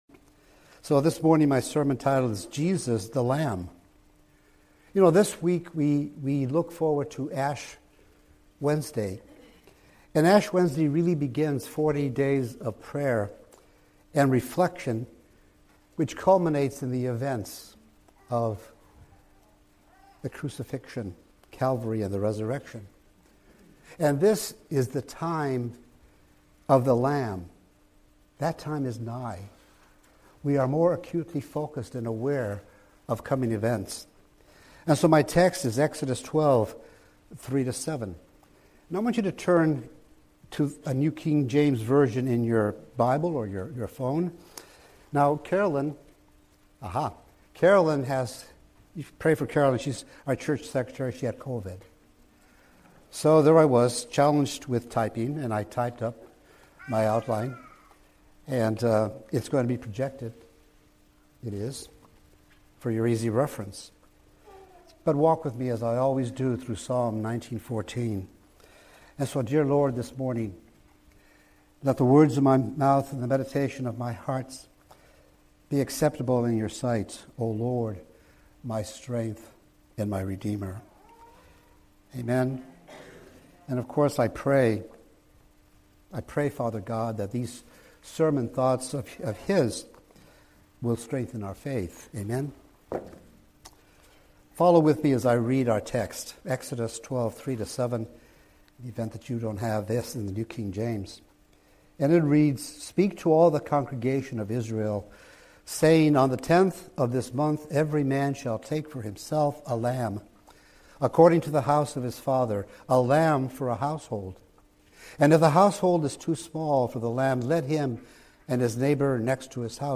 Weekly sermons from New Hope Chapel, Carolina, RI., an evangelical community church.